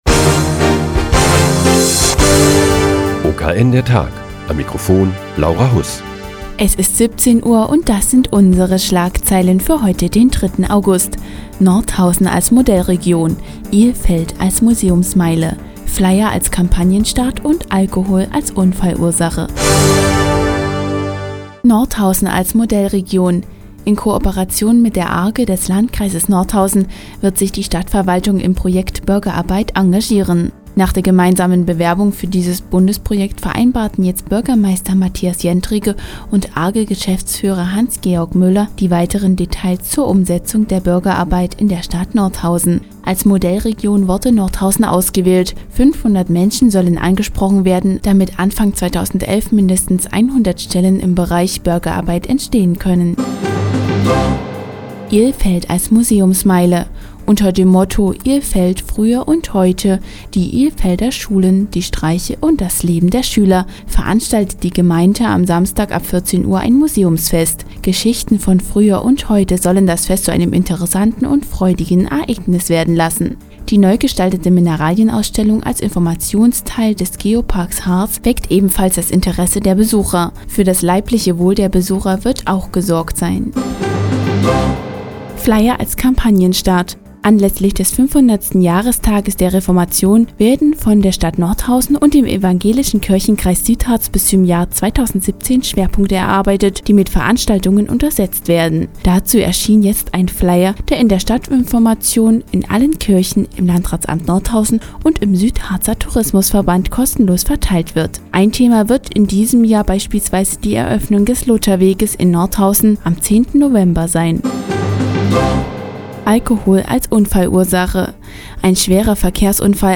Die tägliche Nachrichtensendung des OKN ist nun auch in der nnz zu hören. Heute geht es um das Museumsfest in Ilfeld und um einen schweren Verkehrsunfall auf der B4.